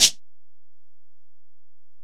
Percs
Shaker (7).wav